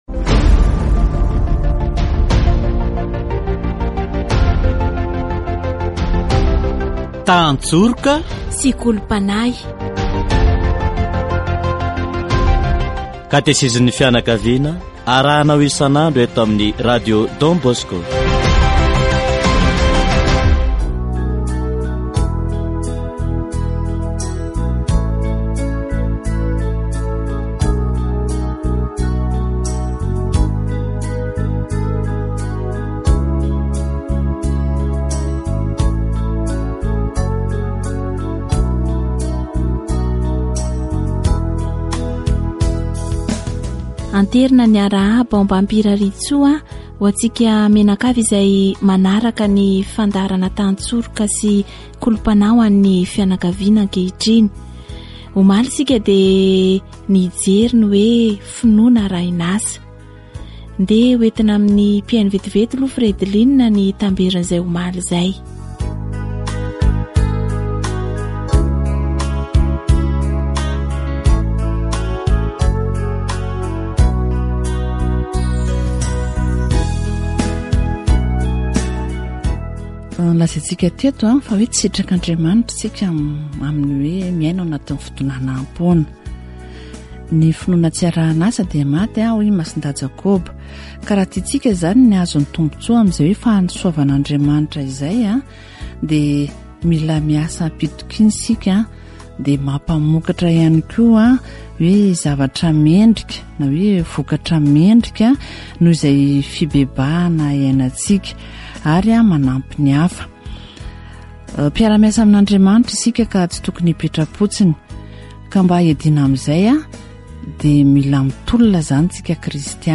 Katesizy momba ny fidonanam-poana